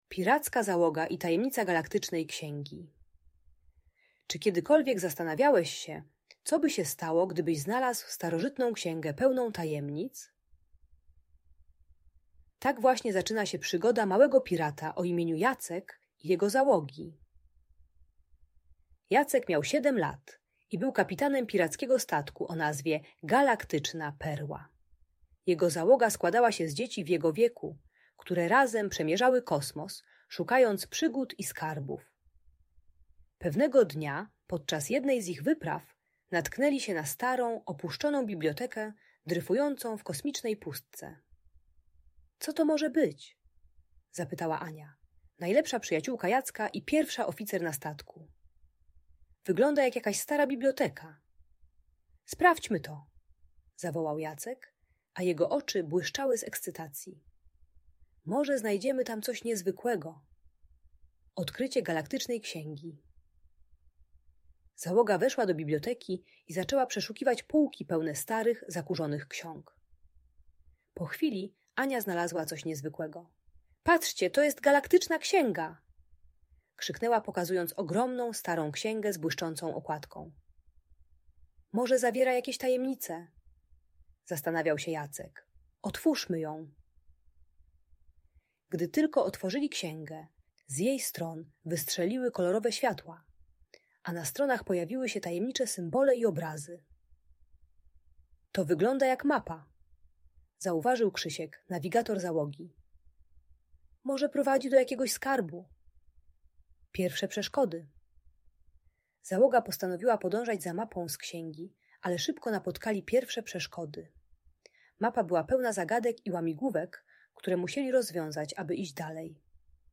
Piracka Załoga i Tajemnica Galaktycznej Księgi - Audiobajka